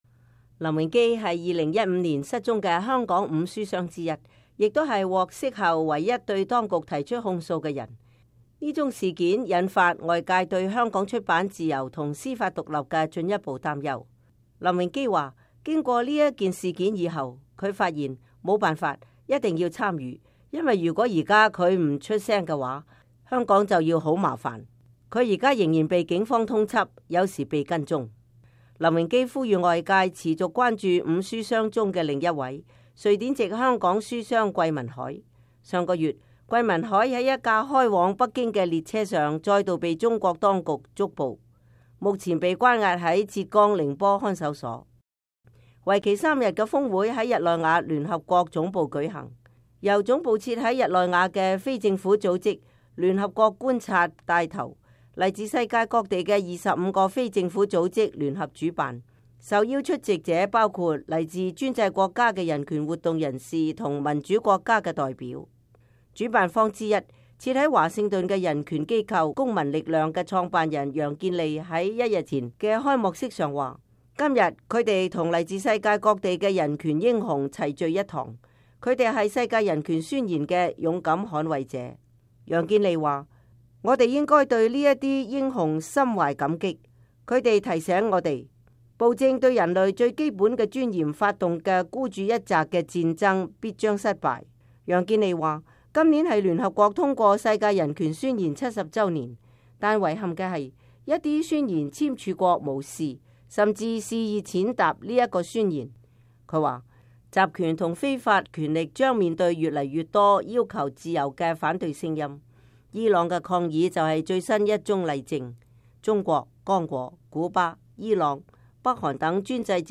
為期三天的峰會在日內瓦聯合國總部舉行，由總部設在日內瓦的非政府組織“聯合國觀察”牽頭，來自世界各地的25個非政府組織聯合主辦。